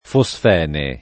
fosfene [ fo S f $ ne ]